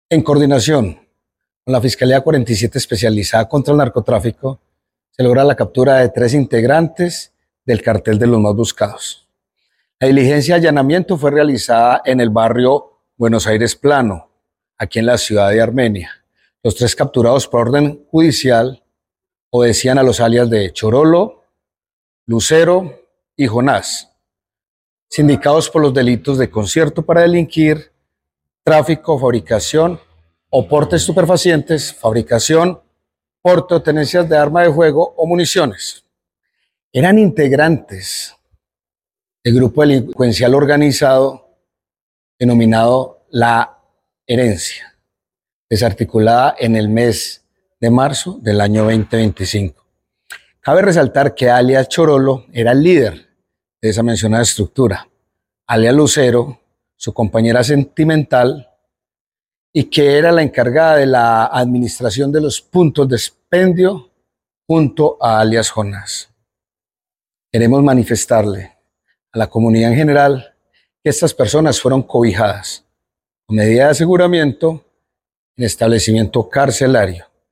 Coronel, Carlos Mario Bustamante, comandante Policía, Quindío